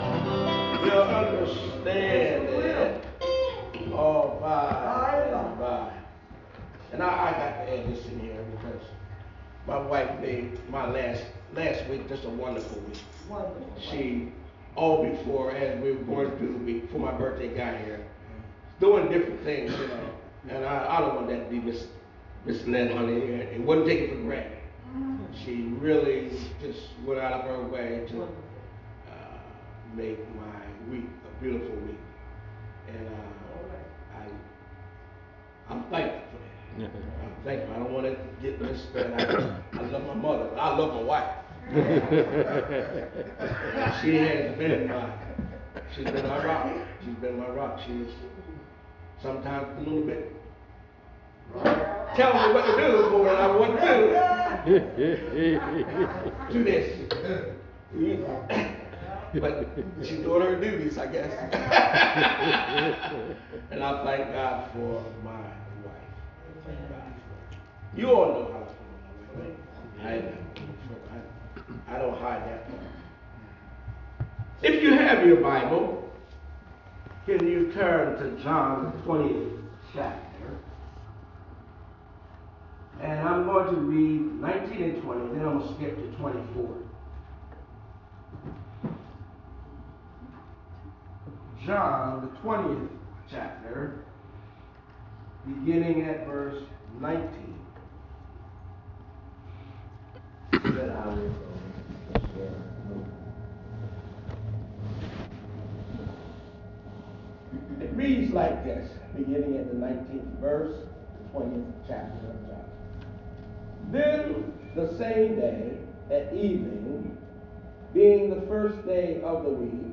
Sermons | Solid Rock Baptist Church - New Castle